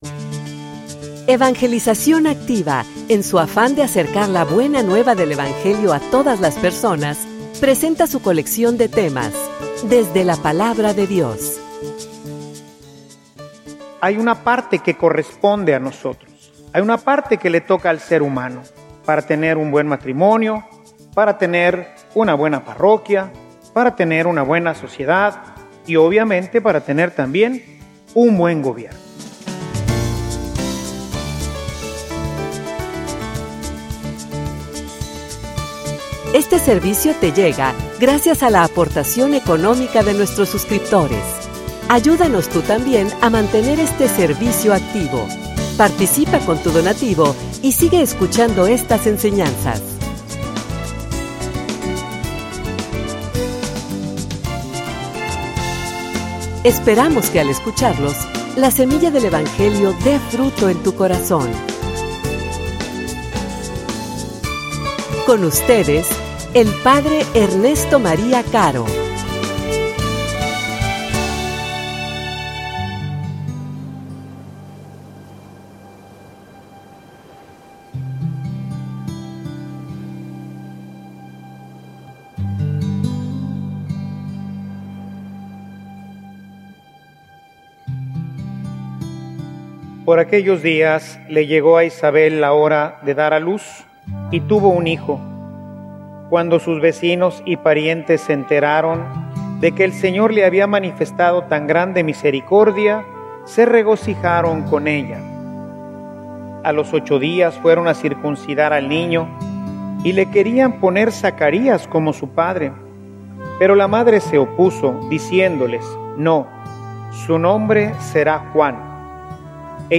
homilia_Que_va_a_ser_de_Mexico.mp3